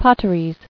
[Pot·ter·ies]